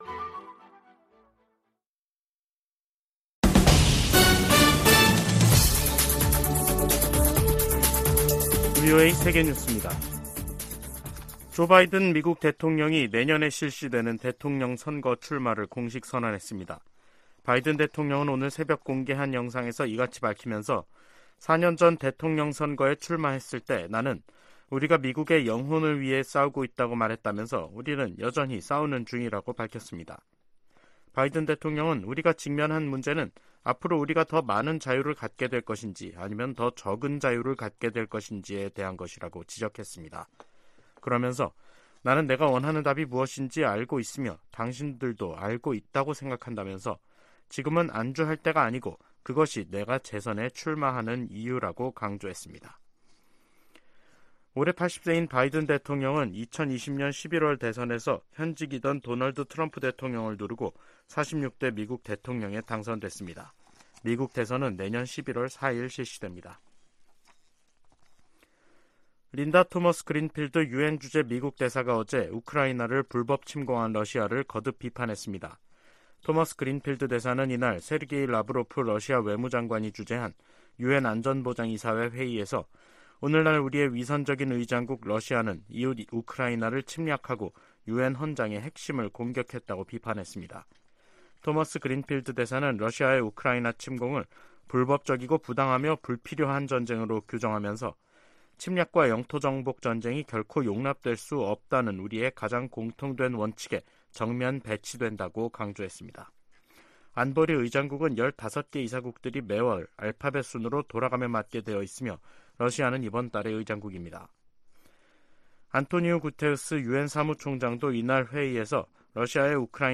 VOA 한국어 간판 뉴스 프로그램 '뉴스 투데이', 2023년 4월 25일 2부 방송입니다. 미국 국빈 방문 일정을 시작한 윤석열 한국 대통령은 미국과 한국이 '최상의 파트너'라며, 행동하는 동맹을 만들겠다고 강조했습니다. 존 커비 백악관 국가안보회의(NSC) 전략소통조정관은 미국의 확장억제 강화 방안이 미한 정상회담 주요 의제가 될 것이라고 밝혔습니다. 미 상원과 하원에서 윤 대통령의 국빈 방미 환영 결의안이 발의됐습니다.